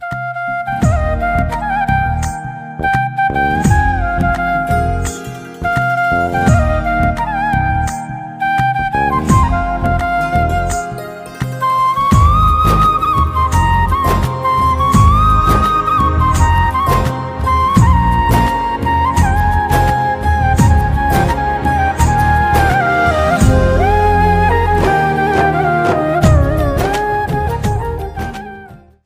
флейта , индийские
без слов